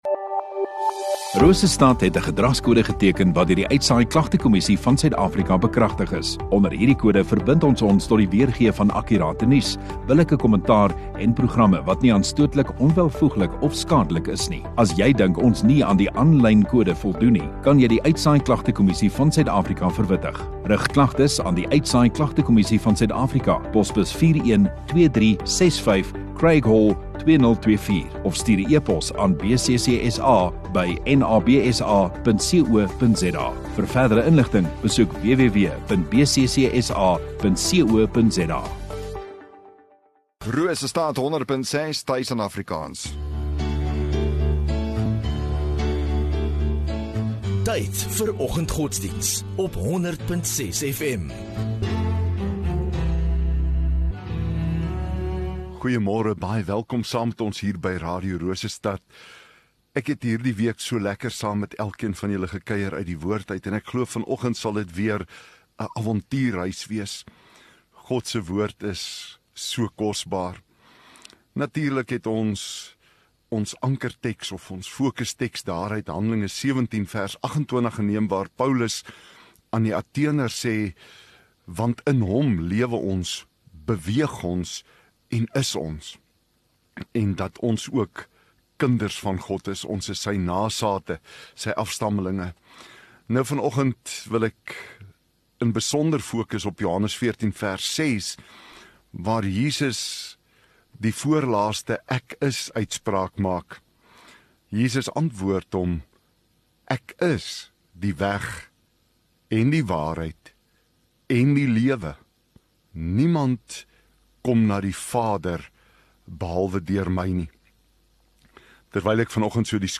22 Aug Vrydag Oggenddiens